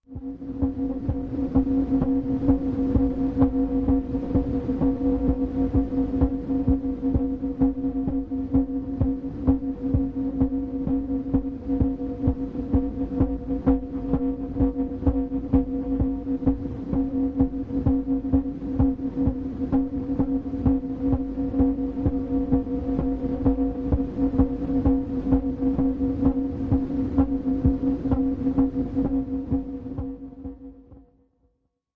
Tin Heart, Machine, Metallic Heart Pulse, Rhythmic, Bell Tone